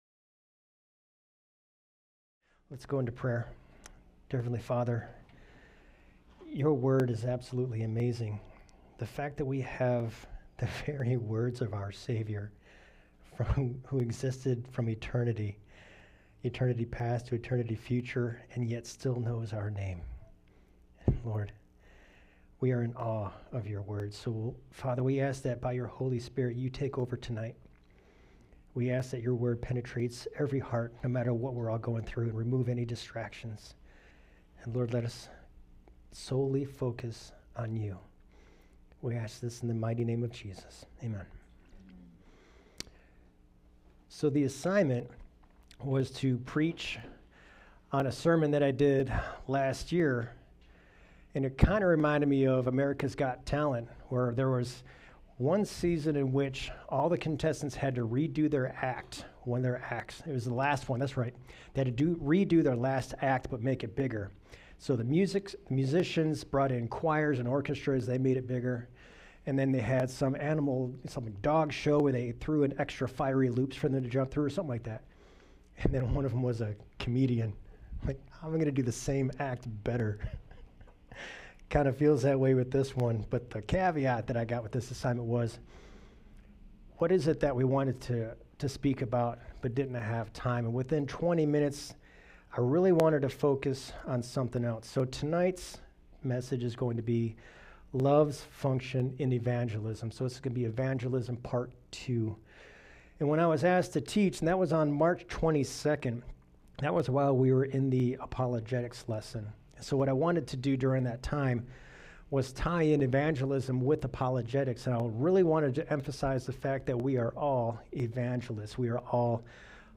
All Sermons Evangelism Part 2 January 31